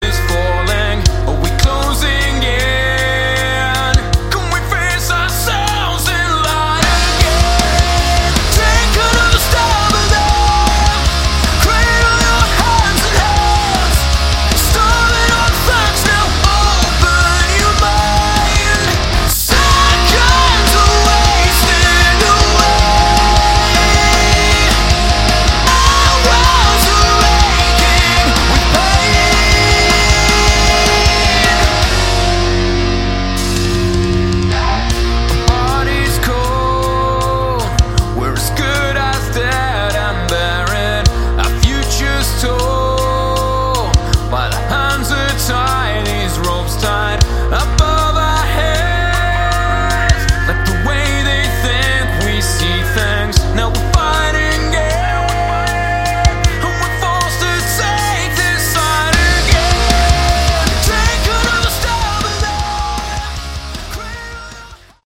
Category: Modern Hard Rock
vocals, bass
guitar, vocals
drums